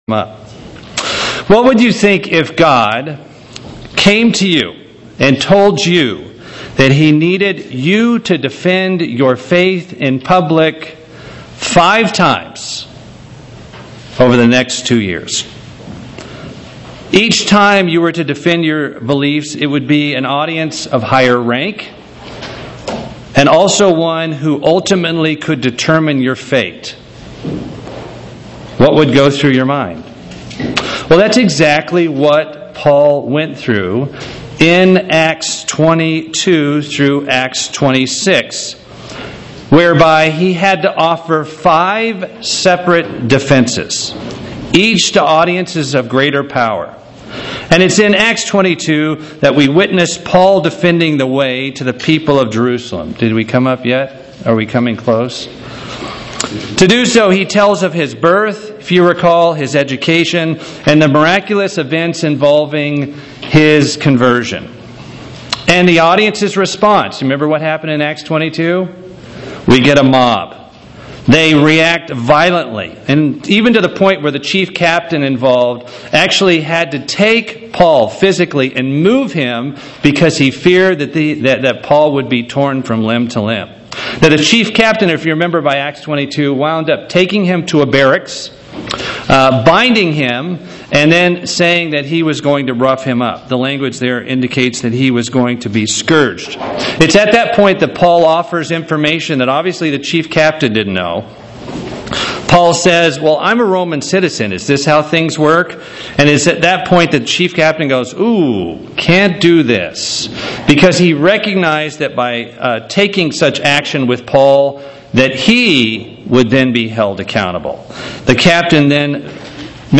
Given in Atlanta, GA
UCG Sermon Studying the bible?